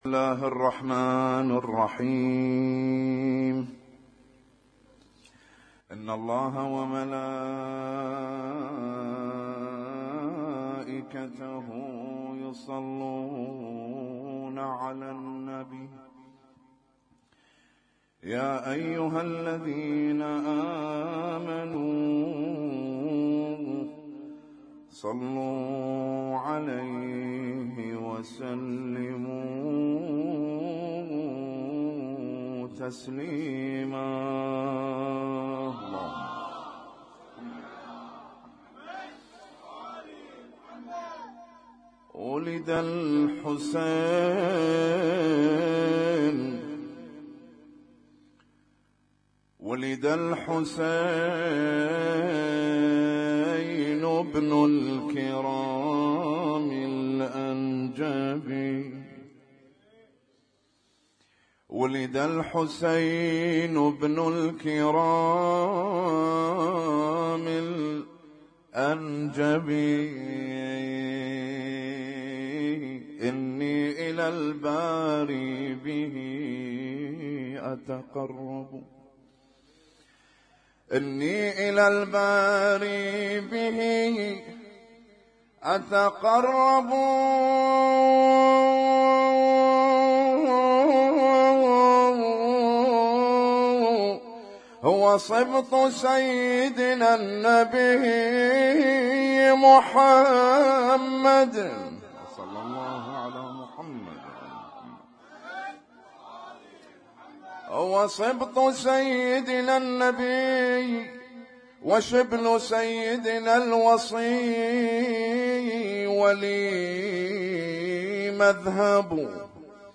Husainyt Alnoor Rumaithiya Kuwait
اسم النشيد:: مولد الأقمار الثلاثة عليهم السلام - 1438